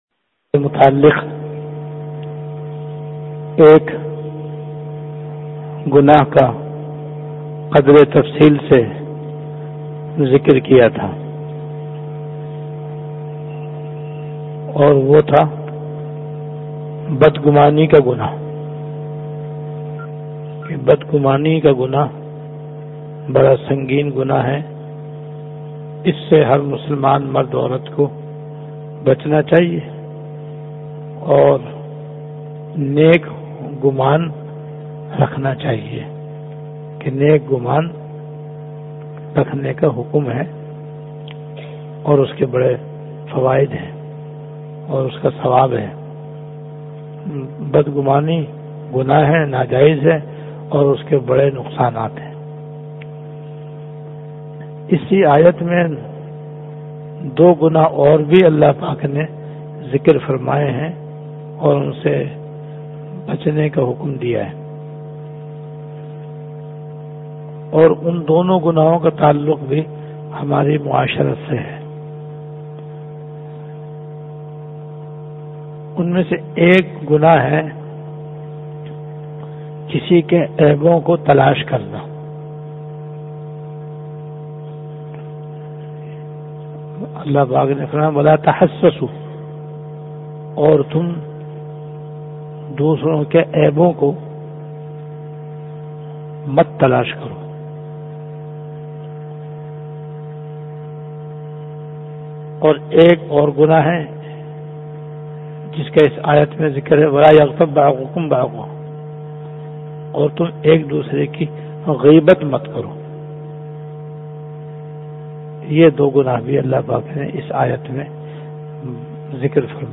Bayanat · Jamia Masjid Bait-ul-Mukkaram, Karachi